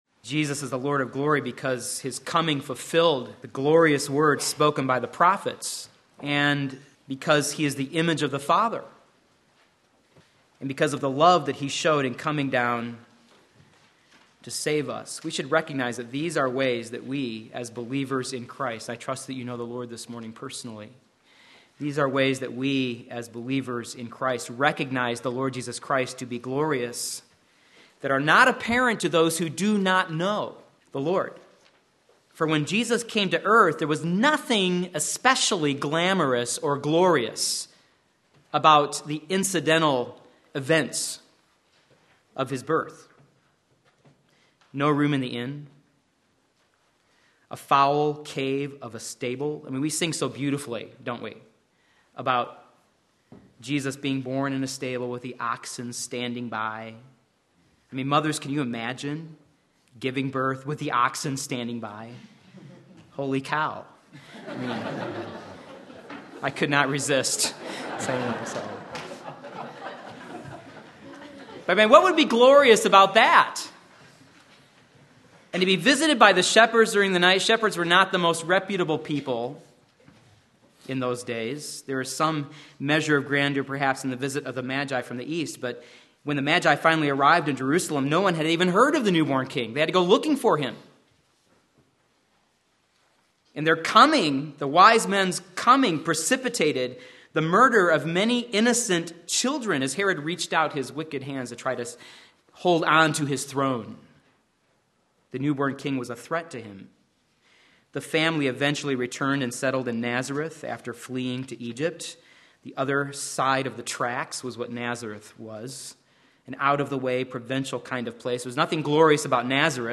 Sermon Link
Behold His Glory John 1:14 Sunday Morning Service